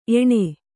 ♪ eṇe